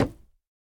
Minecraft Version Minecraft Version latest Latest Release | Latest Snapshot latest / assets / minecraft / sounds / block / bamboo_wood / break2.ogg Compare With Compare With Latest Release | Latest Snapshot
break2.ogg